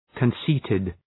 Προφορά
{kən’si:tıd}
conceited.mp3